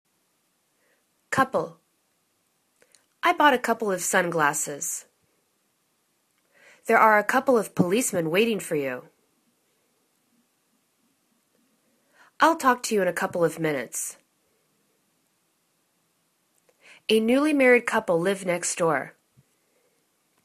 cou.ple     /'kupəl/    n